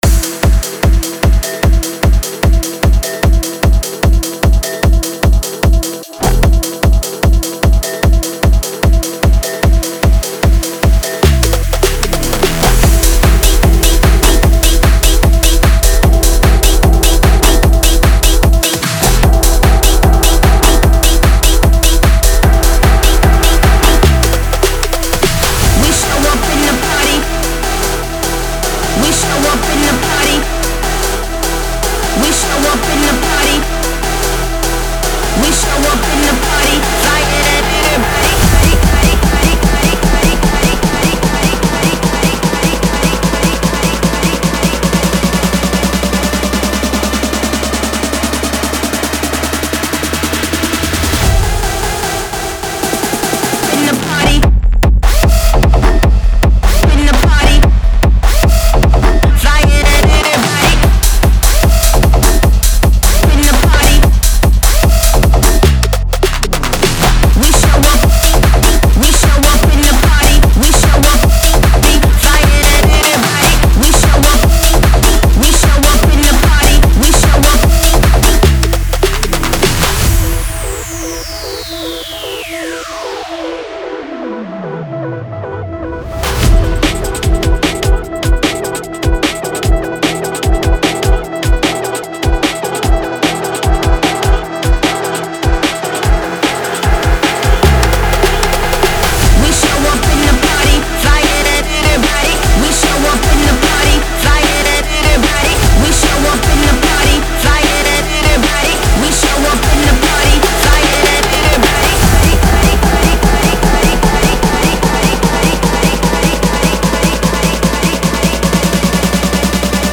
Type: Serum Samples
is a dynamic hardstyle track